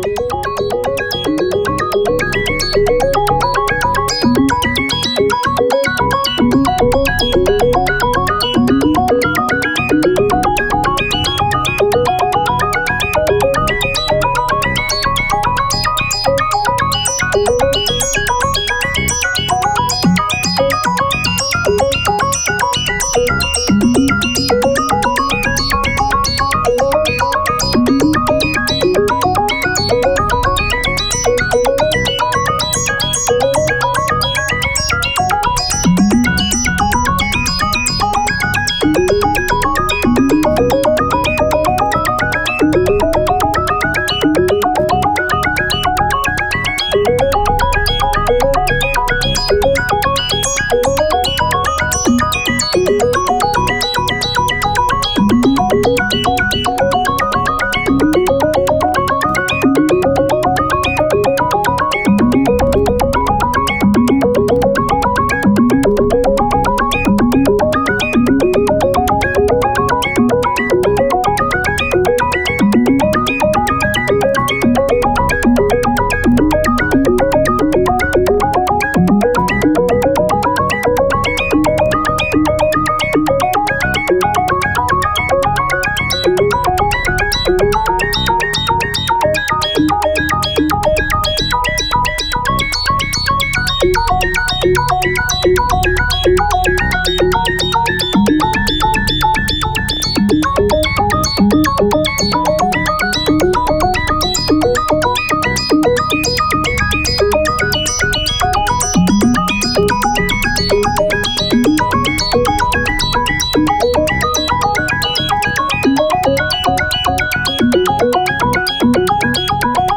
BGM
エレクトロニカニューエイジロング